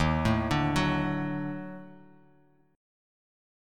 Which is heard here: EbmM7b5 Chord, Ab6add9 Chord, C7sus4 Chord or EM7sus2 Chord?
EbmM7b5 Chord